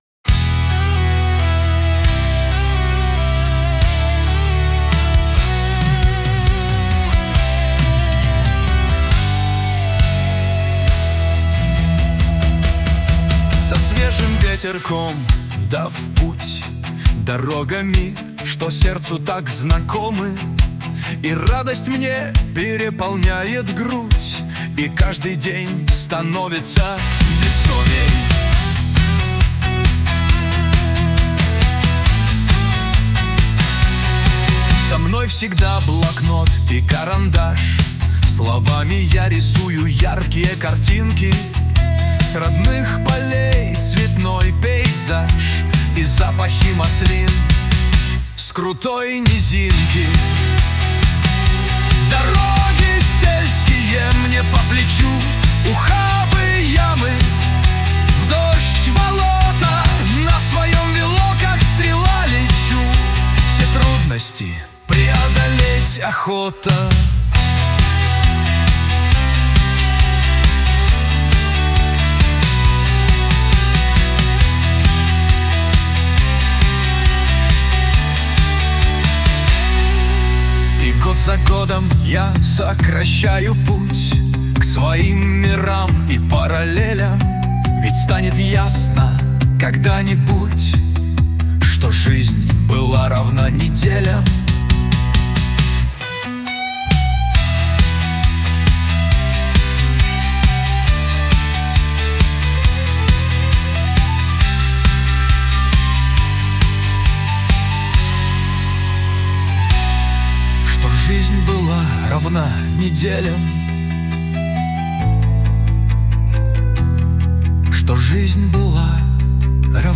ТИП: Пісня
СТИЛЬОВІ ЖАНРИ: Романтичний